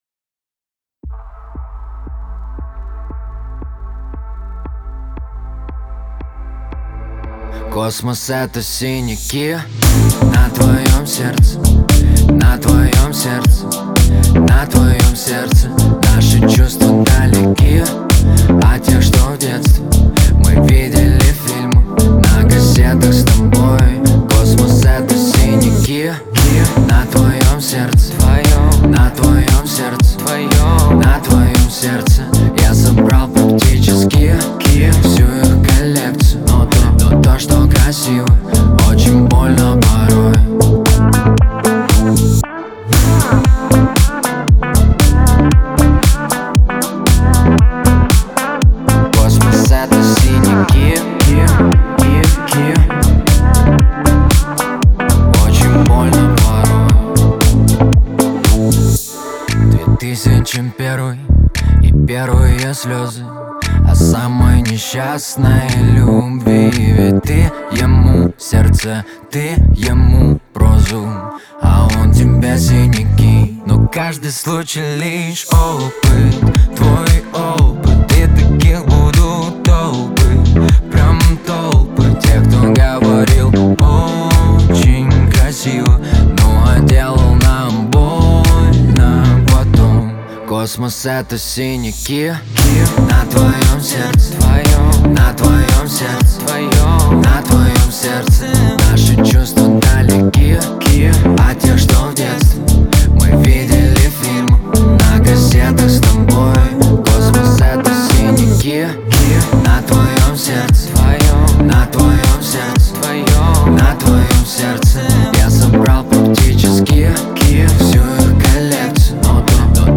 отличается мелодичностью и запоминающимся припевом